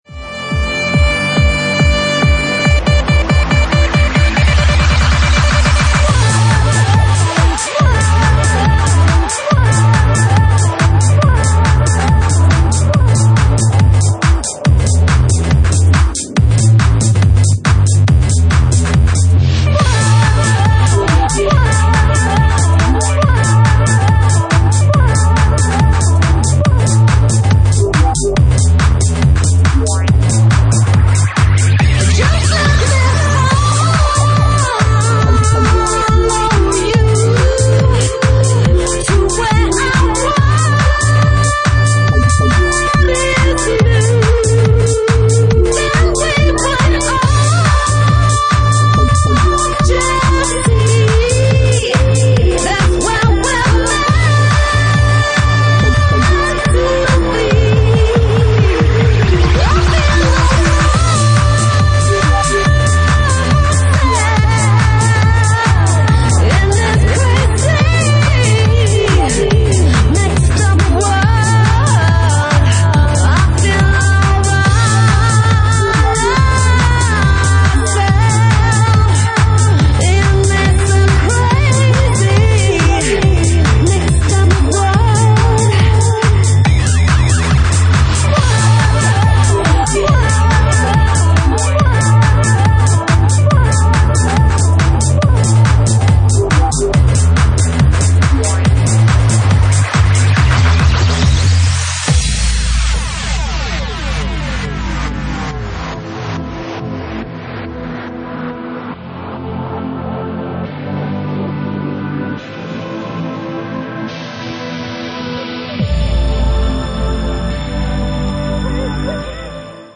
Genre:Bassline House
Bassline House at 140 bpm
Original Mix